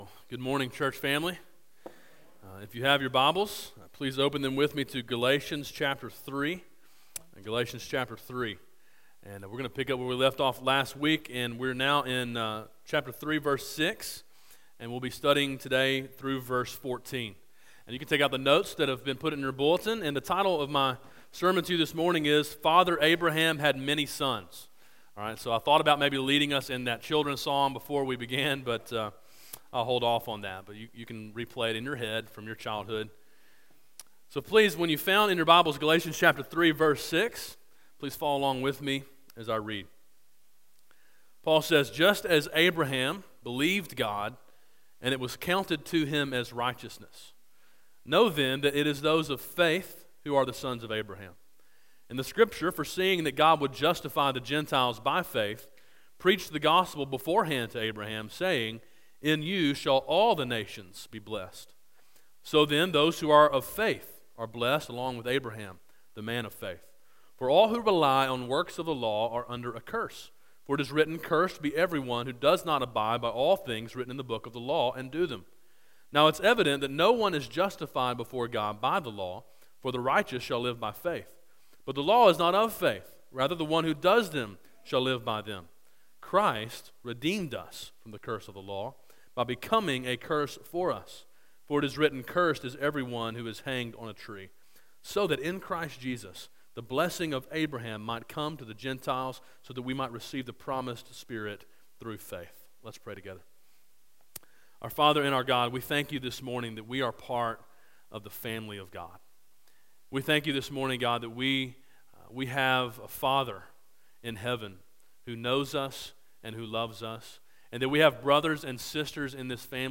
A sermon in a series titled Freedom: A Study of Galatians.